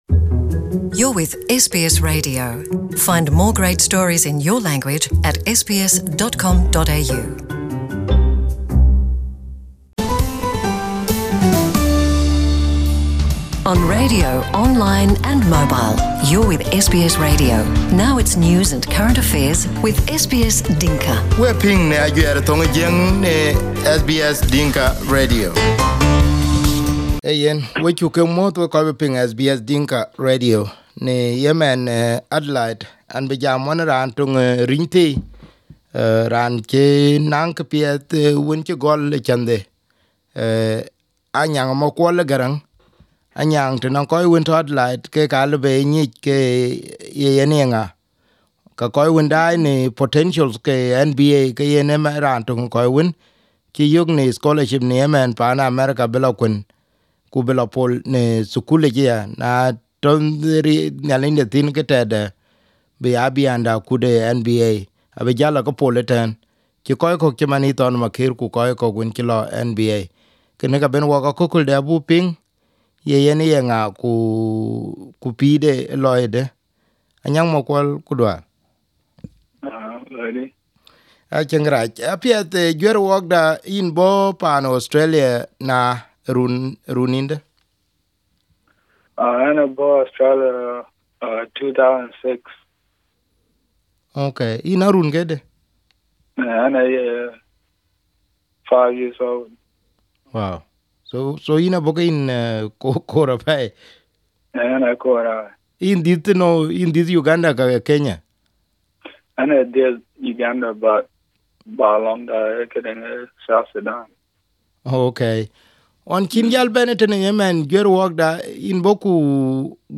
Here is the joined interview from the two.